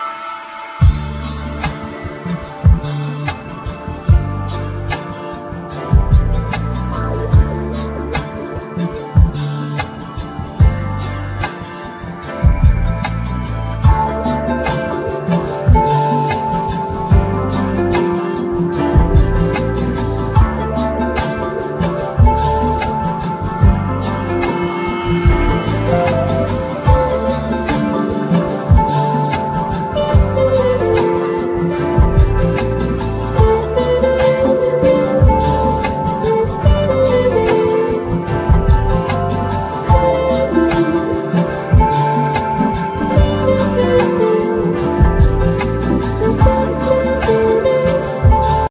Trumpet,Flugelhorn
Bass,Keyboards,Guitar,Samples
Hammond organ,Rhodes piano
Wurlitzer piano
Drums
Vocals
Piano
Drum,Drum programming
Guitar,Pedal steel,Violin